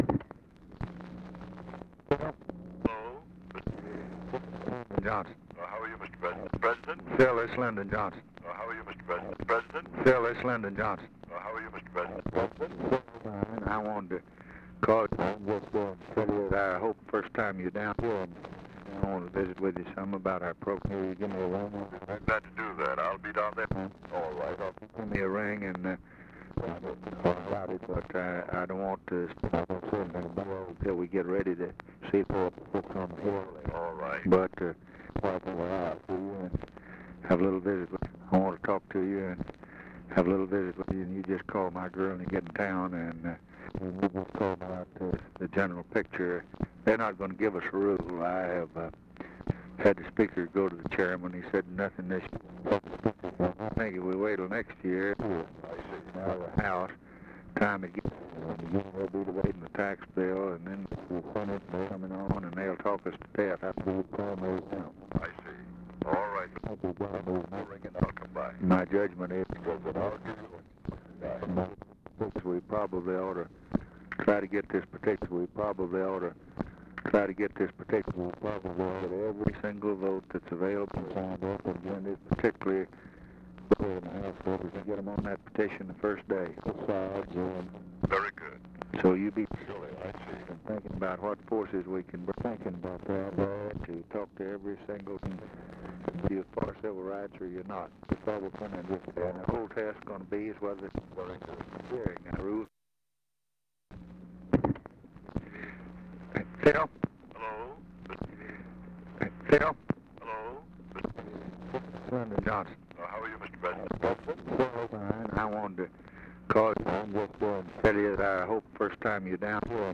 Conversation with A. PHILIP RANDOLPH, November 29, 1963
Secret White House Tapes